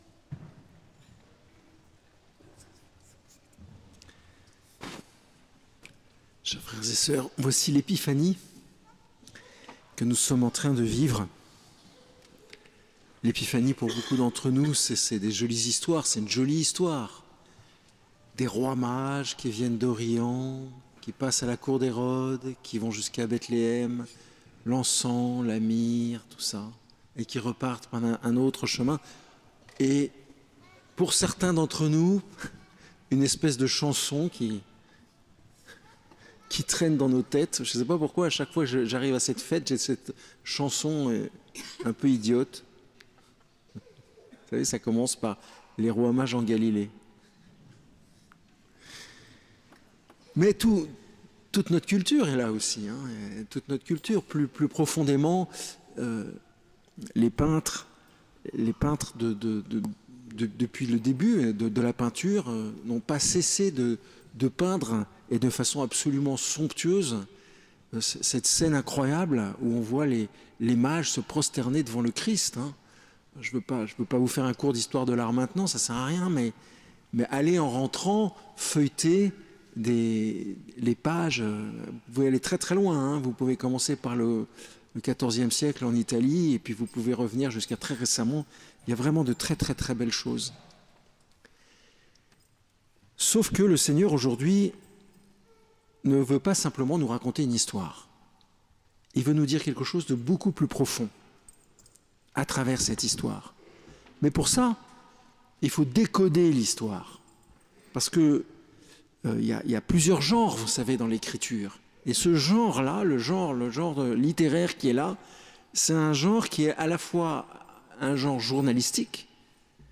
par Basilique Notre-Dame des Victoires | Homélie 2025